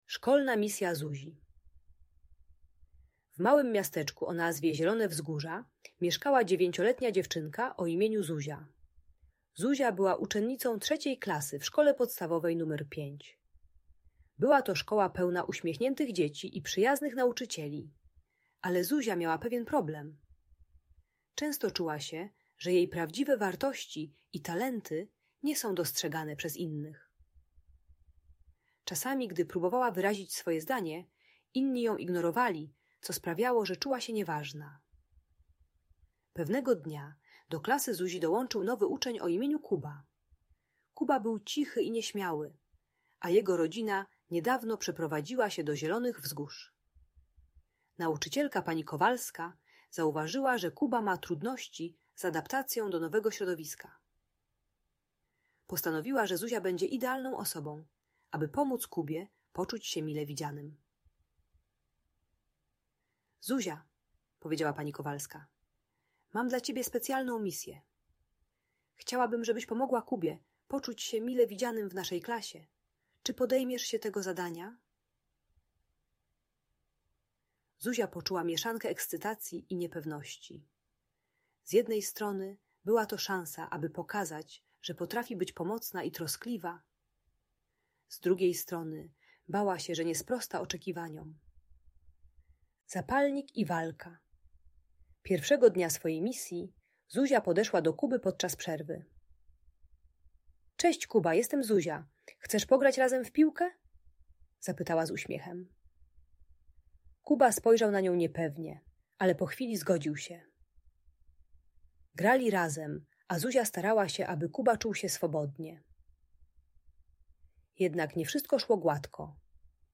Szkolna Misja Zuzi - Inspiring Story of Friendship - Audiobajka dla dzieci